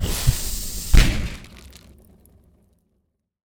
fire-bolt-001-60ft.ogg